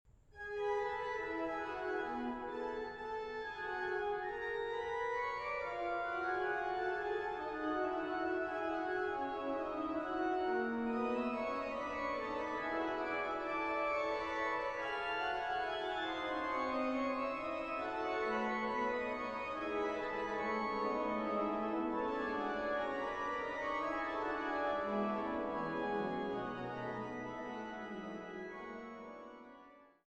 Die Orgeln im St. Petri Dom zu Bremen
Orgel